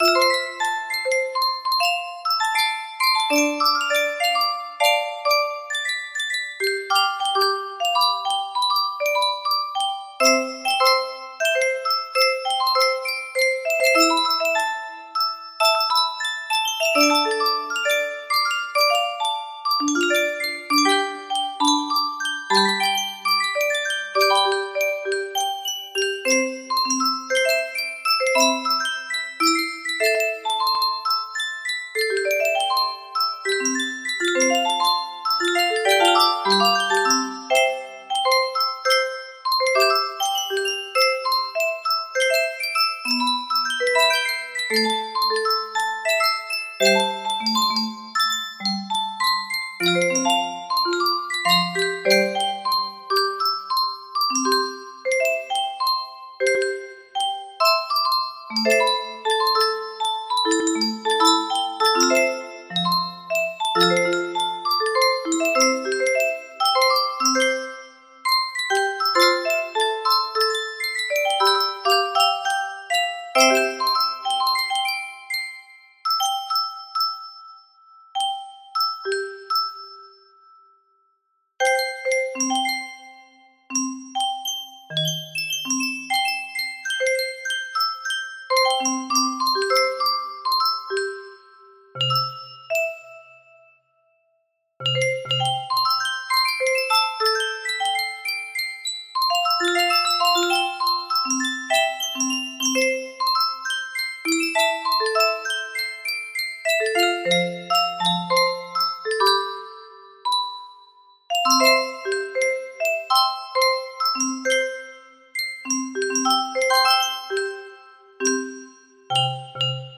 Threads Of Gold 5 music box melody
Full range 60